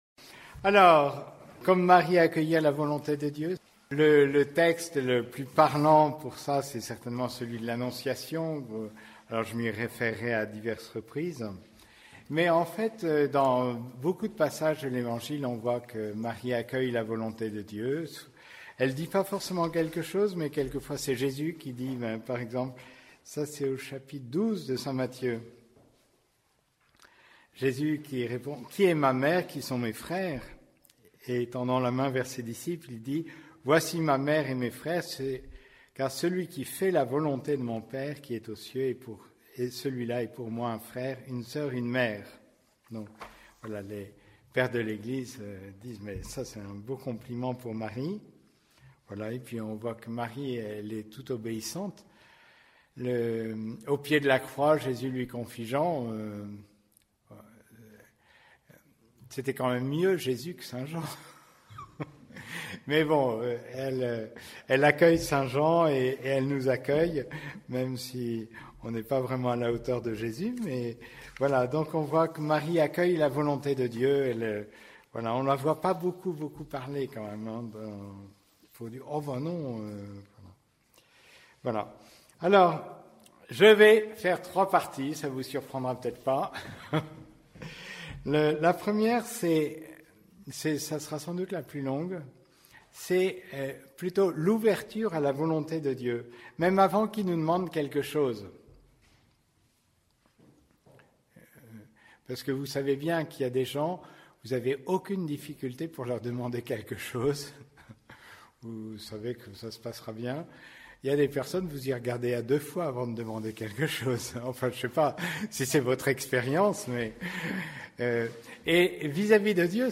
haltes_spirituelles_ourscamp_3_la_volonte_de_dieu_decembre_2024.mp3